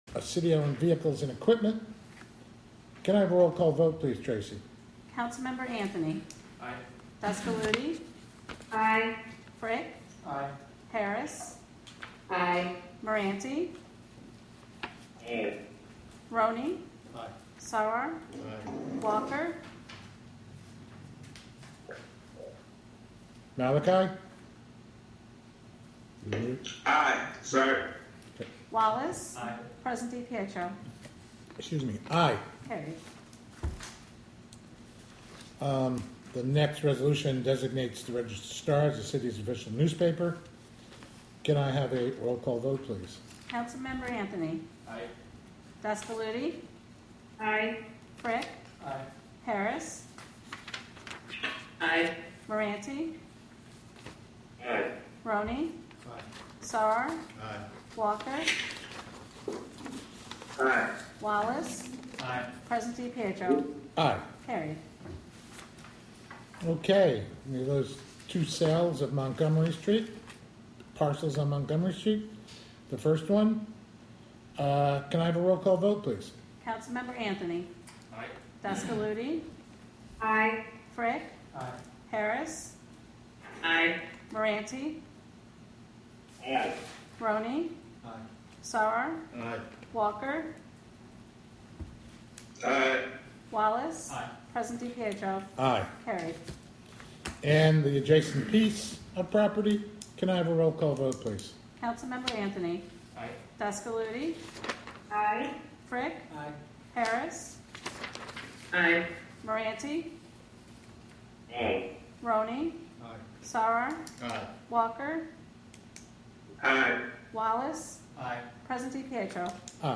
Live from the City of Hudson: Hudson Common Council Formal Meeting (Audio)